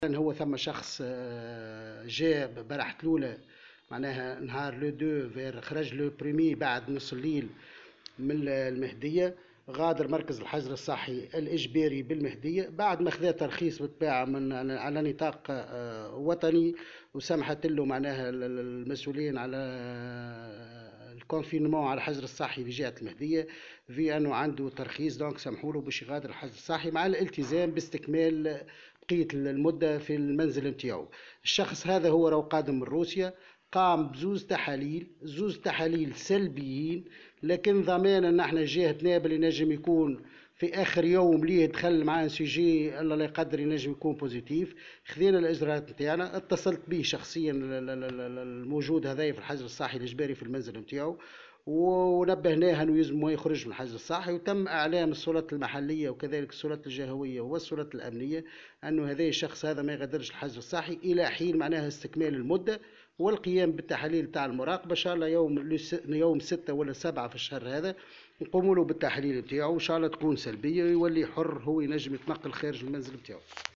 المدير الجهوي للصحة بنابل